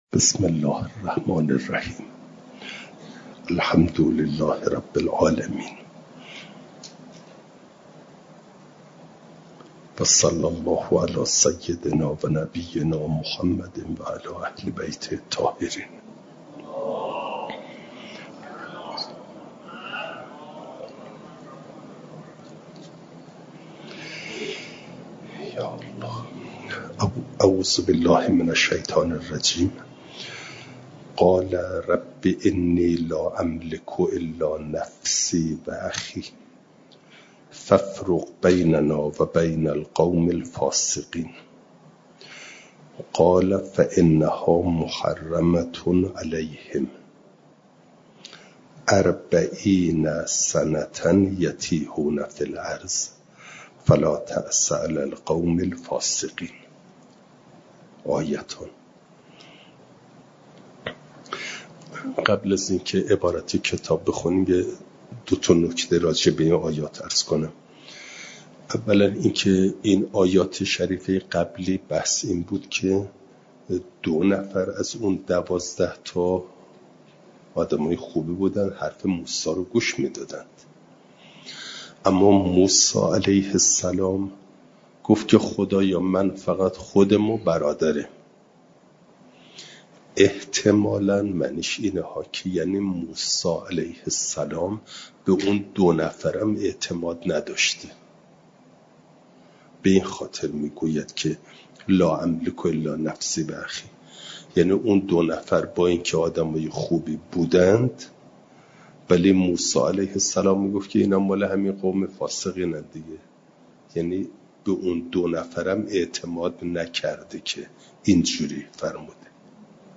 جلسه چهارصد و بیست و هشت درس تفسیر مجمع البیان